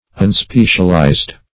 Unspecialized \Un*spe"cial*ized\, a.